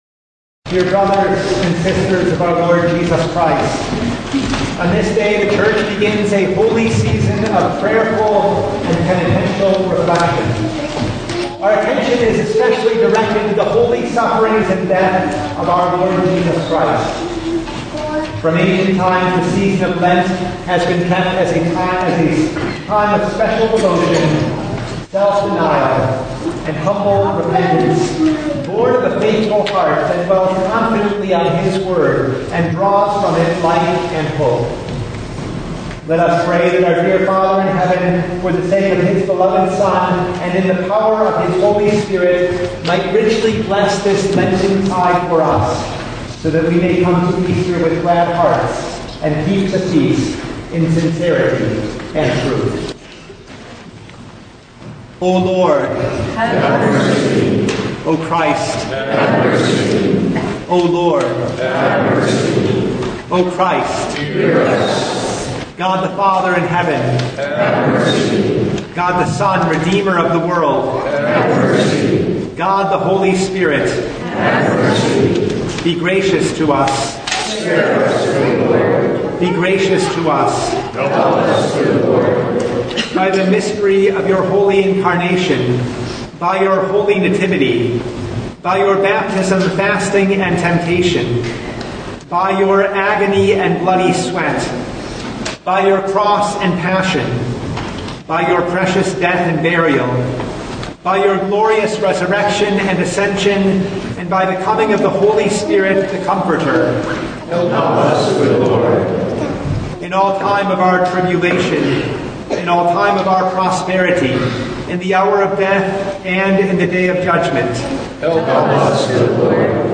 Ash Wednesday Noon Service (2026)
Full Service